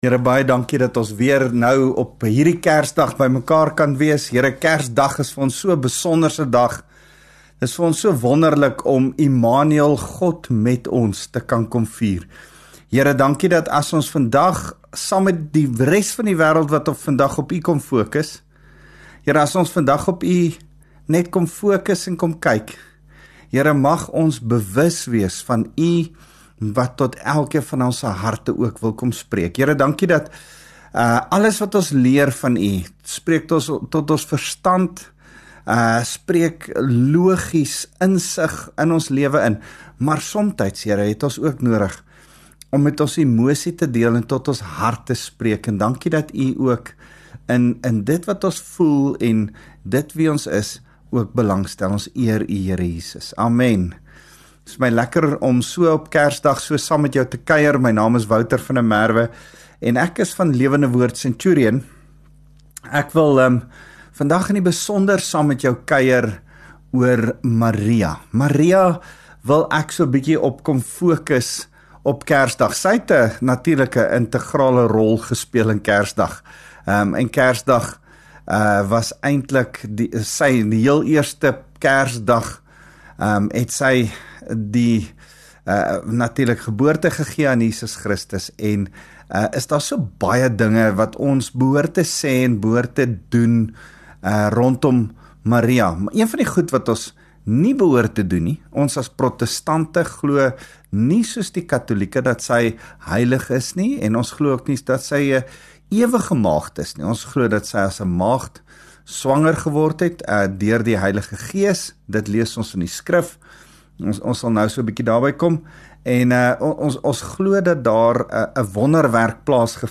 View Promo Continue Install LEKKER FM | Oggendoordenkings